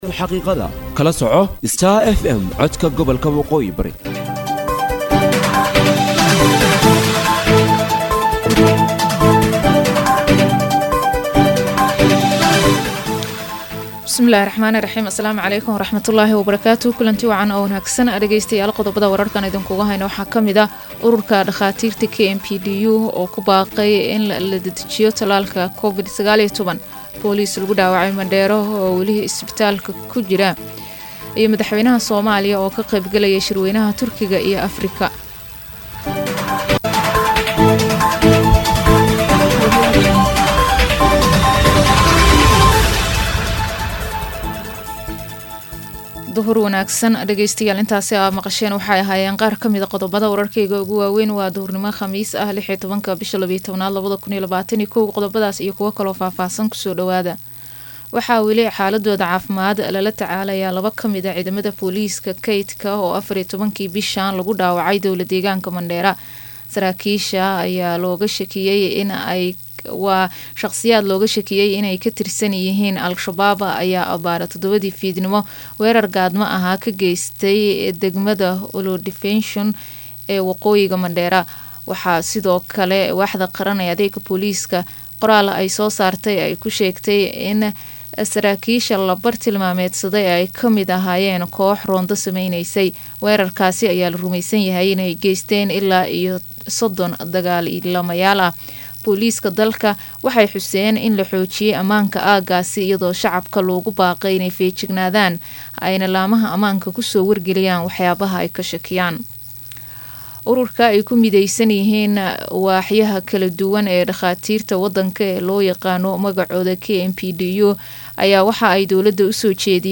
DHAGEYSO:DHAGEYSO:WARKA DUHURNIMO EE IDAACADDA STAR FM